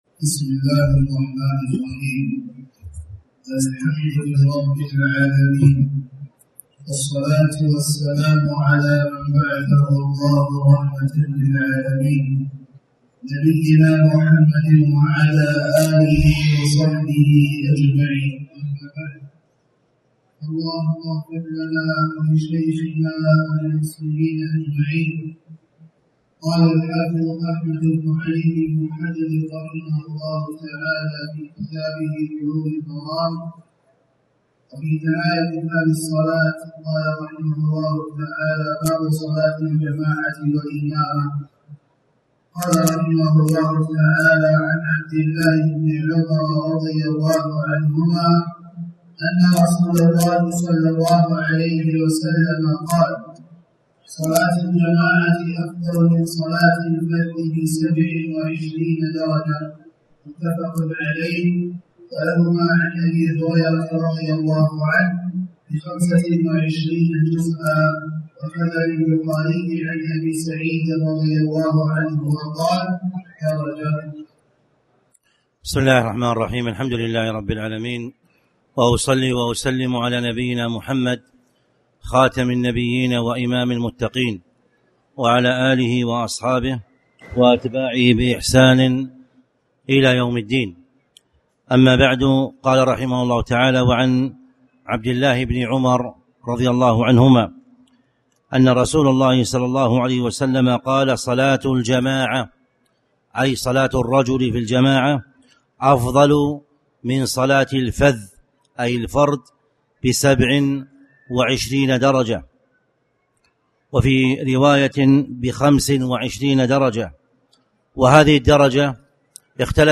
تاريخ النشر ٣٠ محرم ١٤٣٩ هـ المكان: المسجد الحرام الشيخ